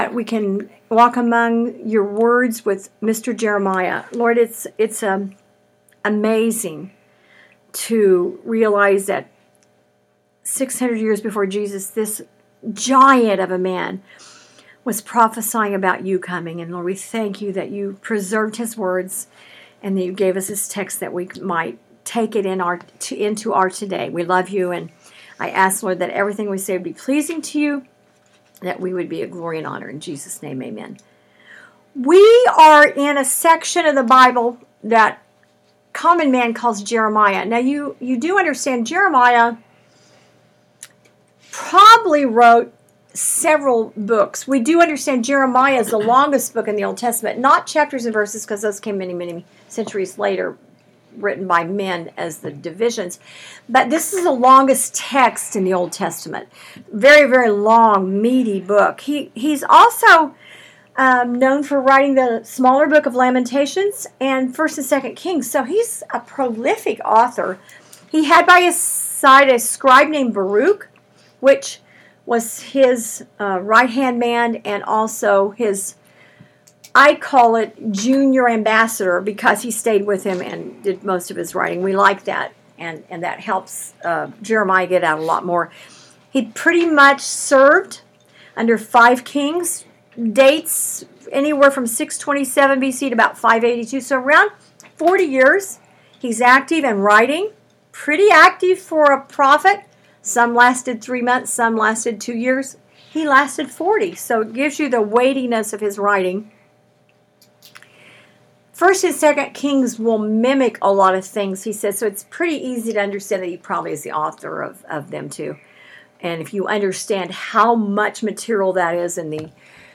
Bible Study Audio and Worksheets